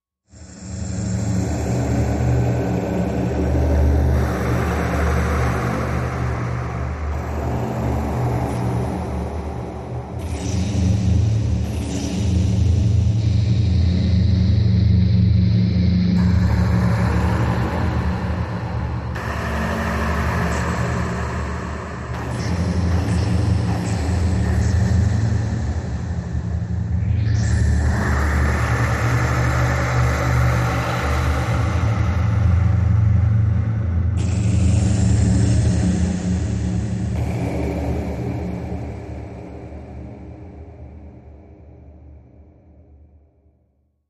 Liquid Fear Distant Waves Deep Hollow Wind Echoes